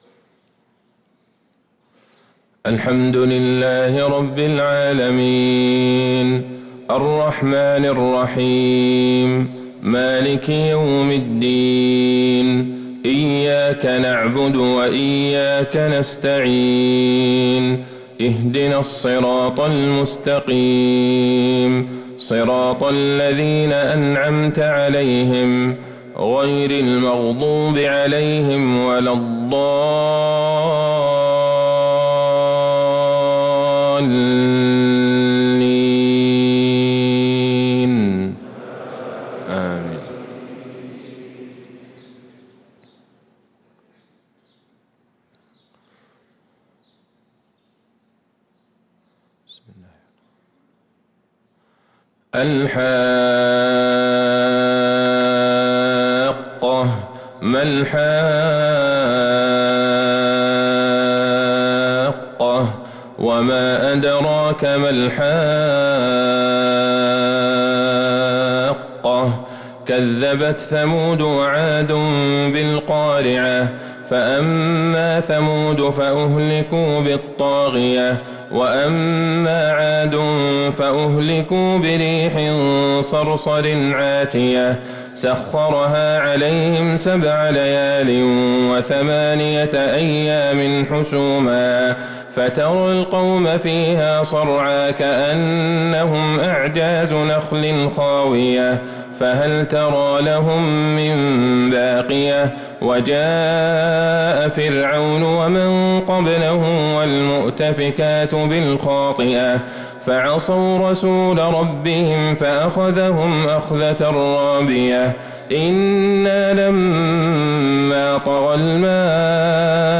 صلاة الفجر للقارئ عبدالله البعيجان 14 جمادي الآخر 1444 هـ